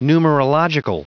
Prononciation du mot : numerological